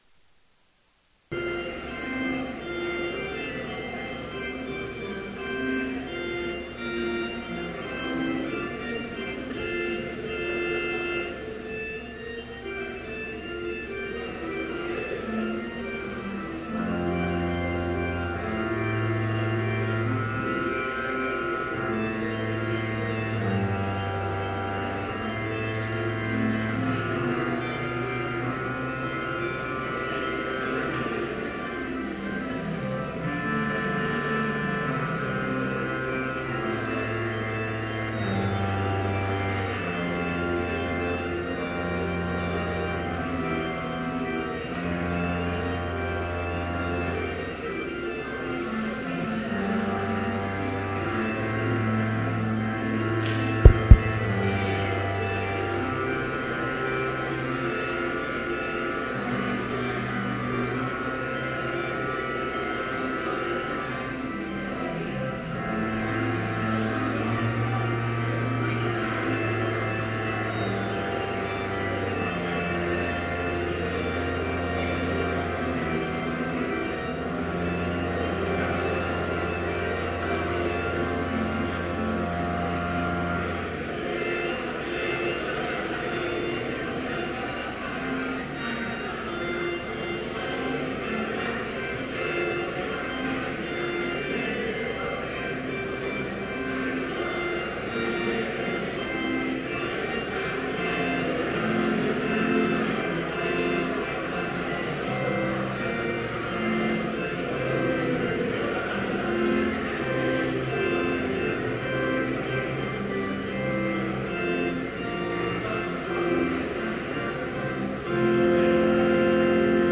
Complete service audio for Chapel - February 24, 2014